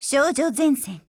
AK74M_TITLECALL_JP.wav